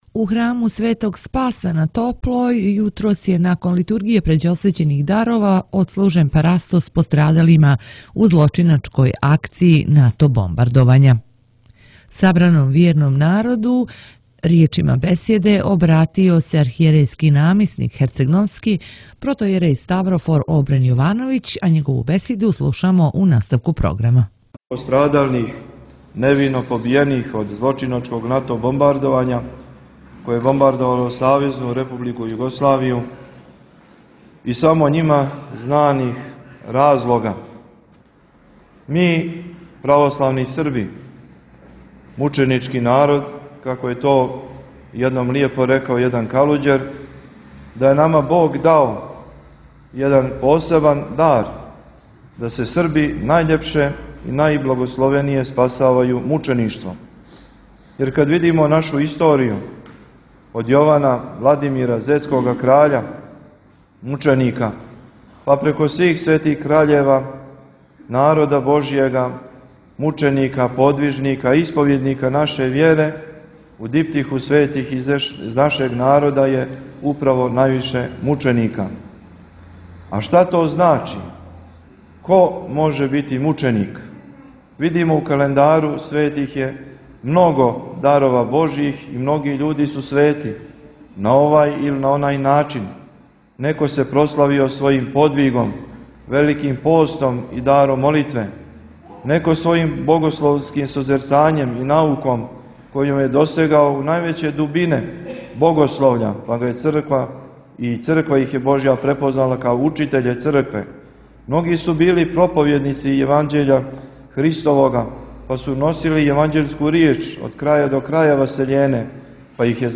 Njegovo visokopreosveštenstvo Mitropolit crnogorsko-primorski g. Joanikije služio je danas, 24. marta, Svetu liturgiju pređeosvećenih darova, u manastiru Ćelija piperska […]